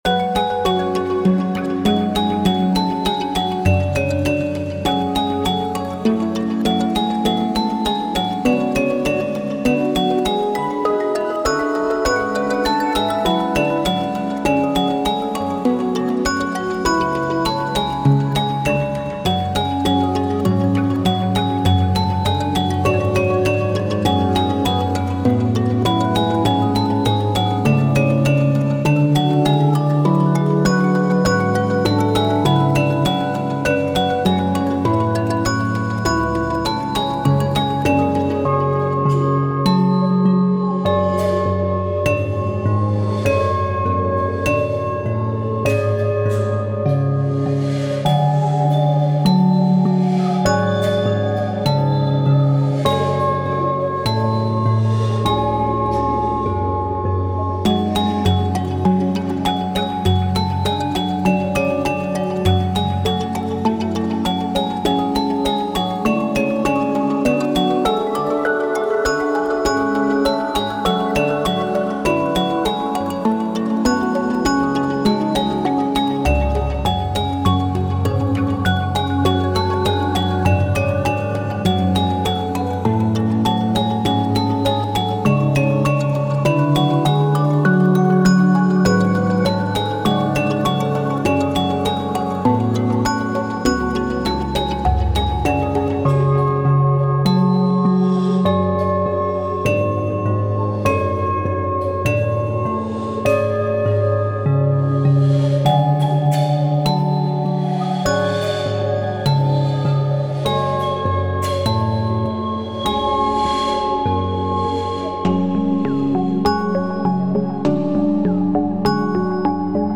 タグ: フィールド楽曲 和風 地下/洞窟 幻想的 コメント: 和風の洞窟フィールドをイメージしたBGM。